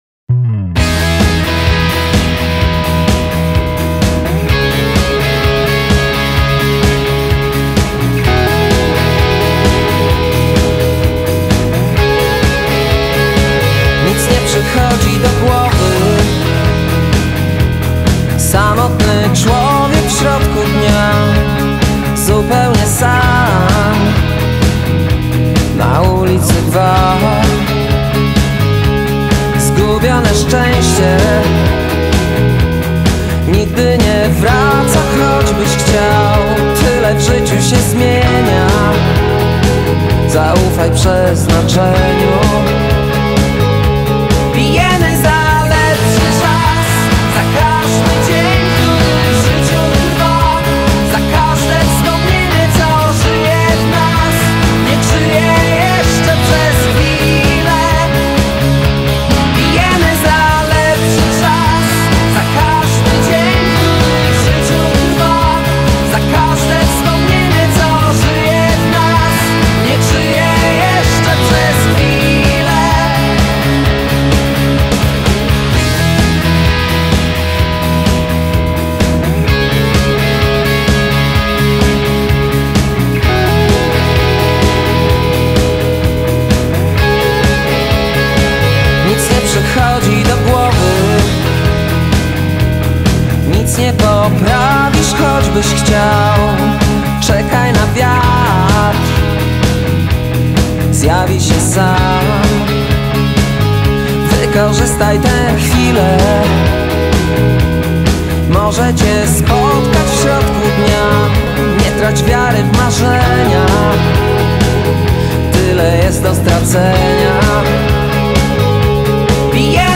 آهنگ راک آهنگ آلترناتیو راک راک لهستانی